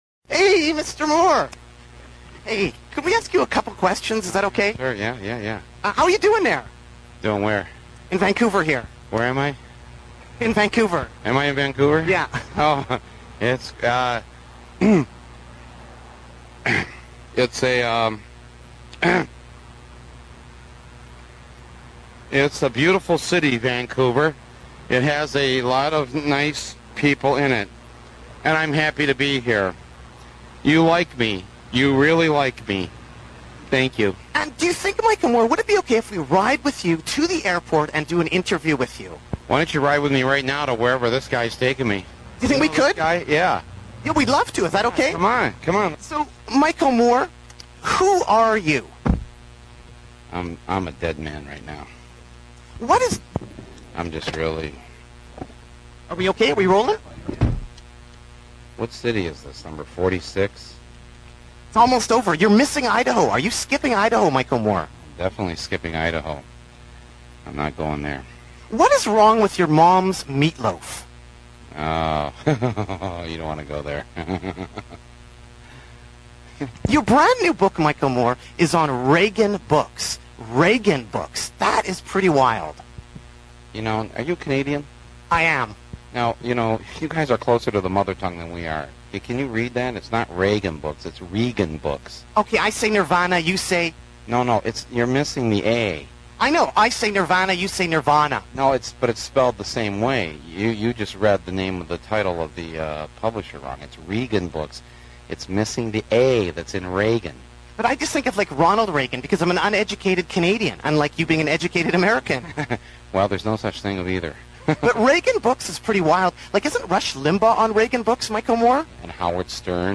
Radio Interviews Archives - Page 59 of 64 - Nardwuar the Human Serviette Radio Show!